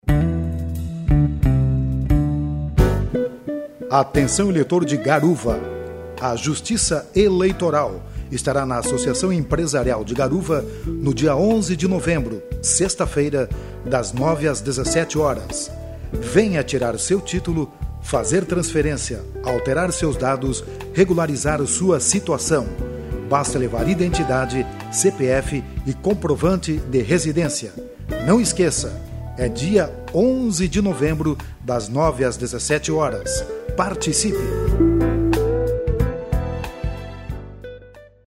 spot_garuva.mp3